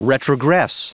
Prononciation du mot retrogress en anglais (fichier audio)
Prononciation du mot : retrogress